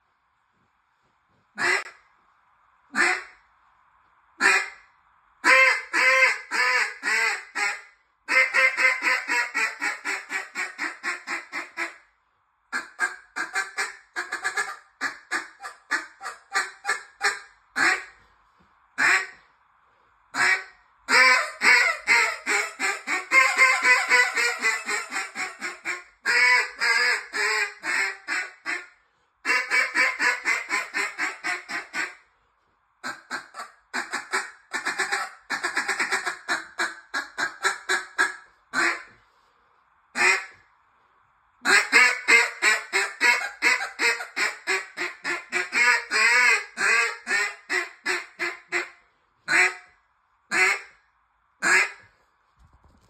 AMBUSH DUCK CALL
Designed in the style of live duck calls, the Ambush boasts impressive top-end hail calls, superb control, and a seamless transition from top to bottom.
Its low-end feed chatter is truly off the charts.